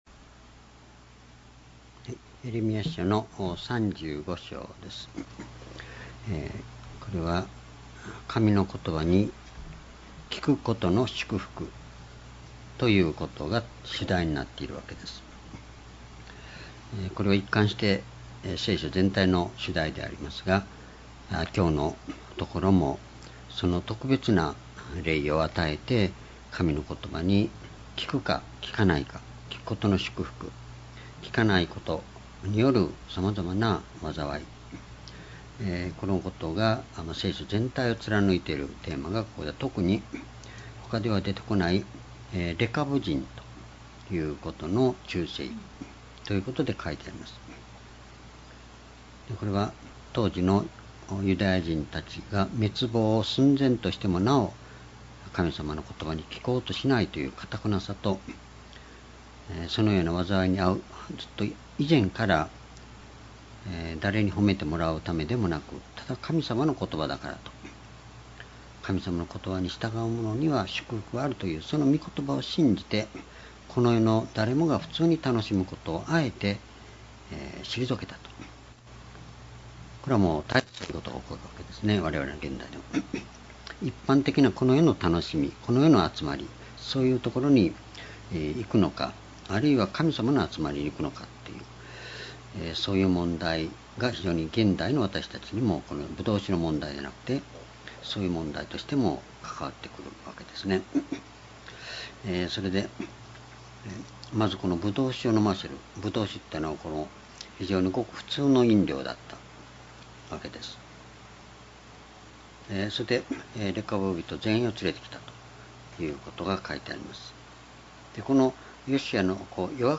講話 ｢神の言葉に聞くことの祝福｣エレミヤ35章-17年08月01日 夕